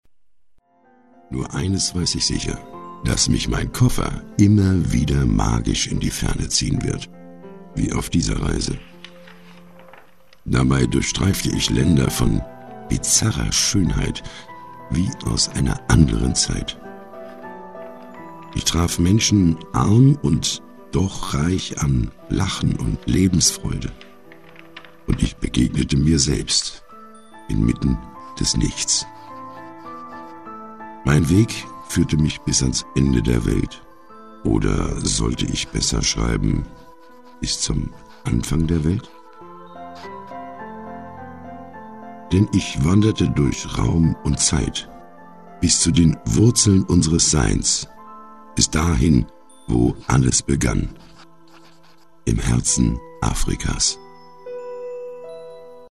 Profisprecher deutsch. Angenehme tiefe Stimme, weiche dunkle Stimme, Seniorstimme, young senior
Sprechprobe: Industrie (Muttersprache):